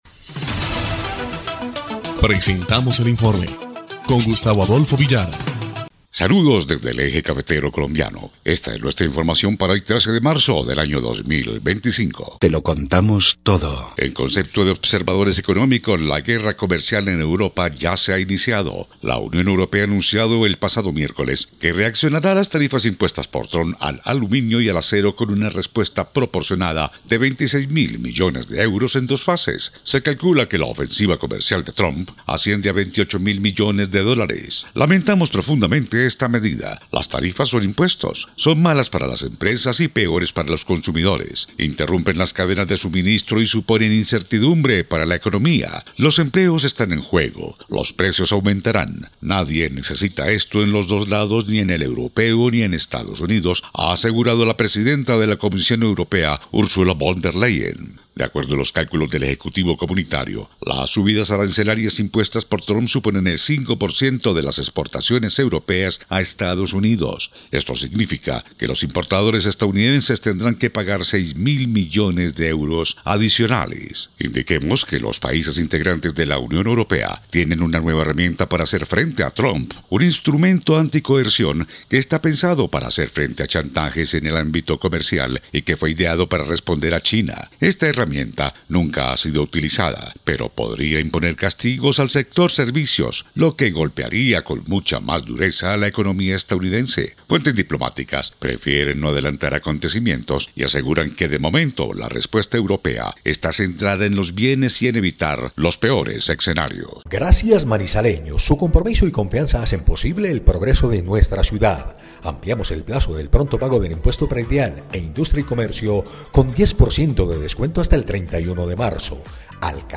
EL INFORME 3° Clip de Noticias del 13 de marzo de 2025